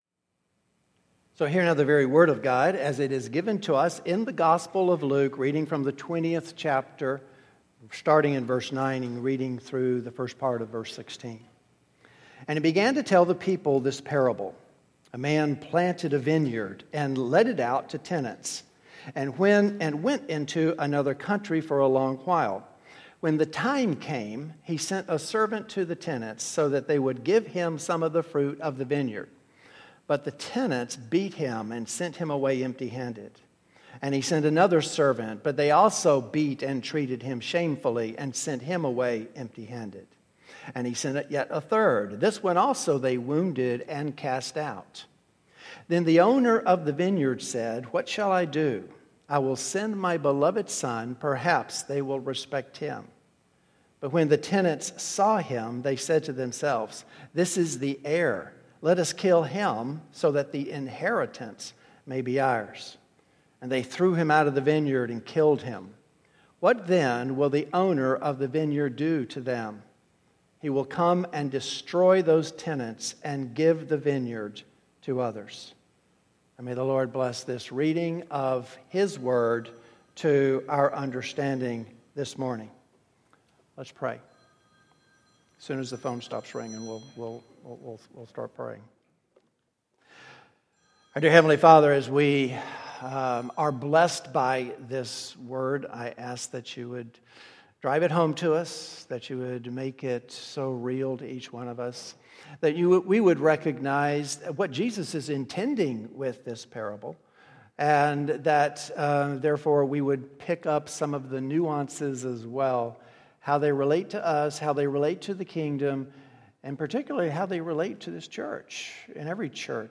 New Hope Sermons